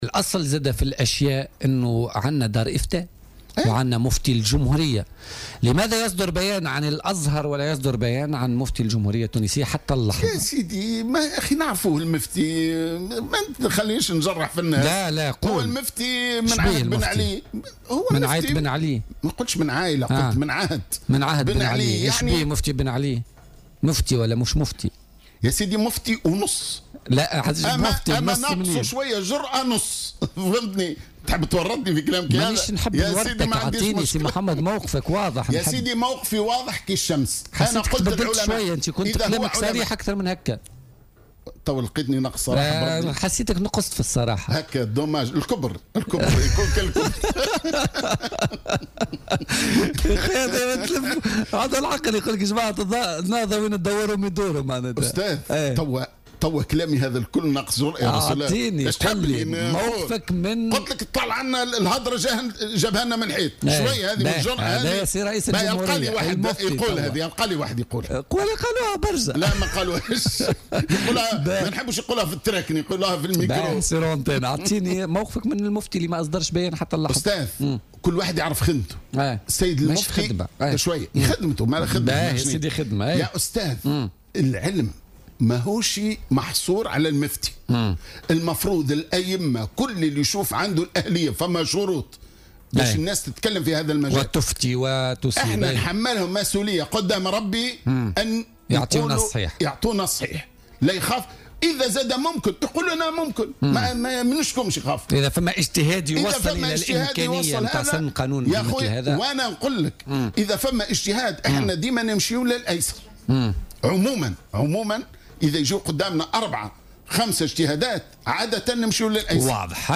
وقال بن سالم، ضيف برنامج "بوليتيكا" اليوم الثلاثاء " المفتي من عهد بن علي وينقصه القليل من الجرأة"، مضيفا أن" العلم لا يقتصر فقط على المفتي بل على كل الائمة الذين تتوفر فيهم الشروط للخوض في الموضوع"، وفق تعبيره.